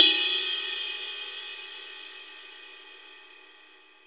Index of /90_sSampleCDs/Best Service - Real Mega Drums VOL-1/Partition G/DRY KIT 1 GM
RIDE 1.wav